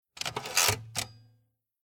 Line shift lever
line-shift-lever.mp3